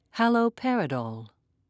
(ha-loe-per'i-dole)